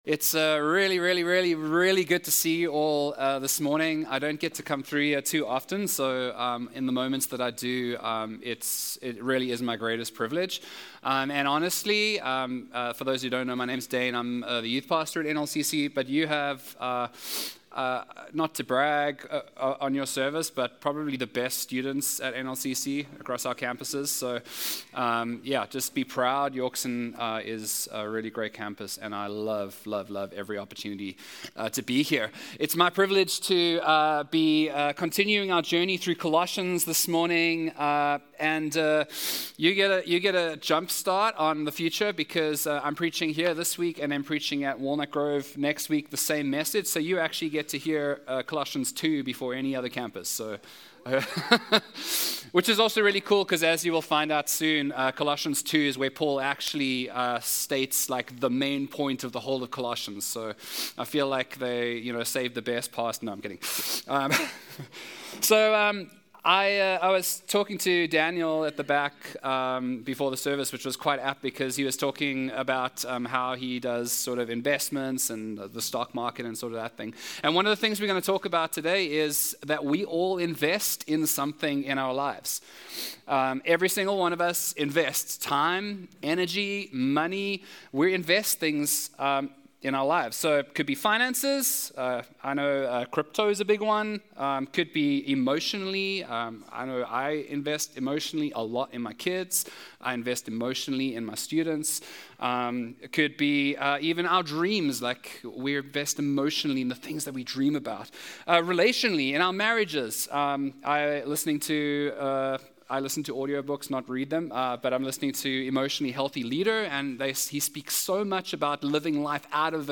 Colossians 2:1-7 Sermon